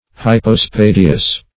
Hypospadias \Hy`po*spa"di*as\, n. [NL., fr. Gr. "ypo` beneath +
hypospadias.mp3